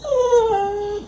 dog
bark_27045.wav